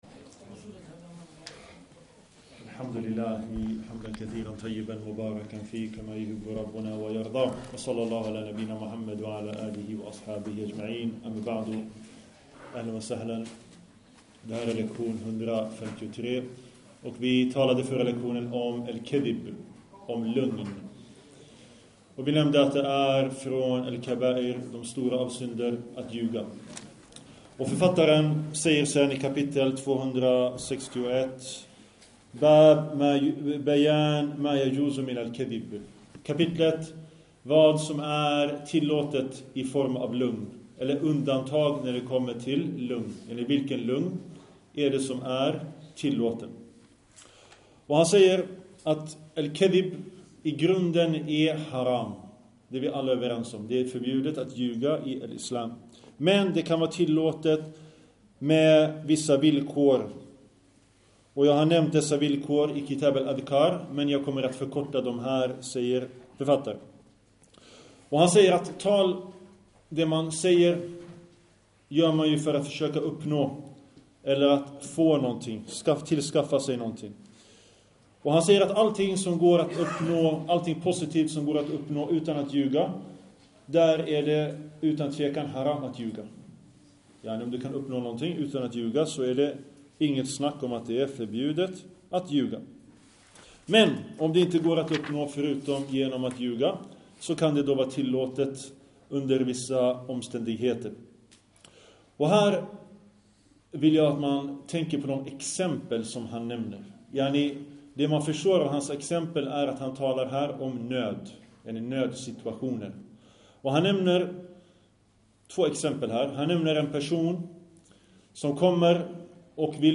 Denna föreläsning handlar om: Finns det tillfällen där lögn inte är haram?